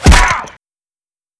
body_medium_impact_hard4.wav